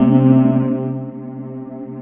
SAILOR M C3.wav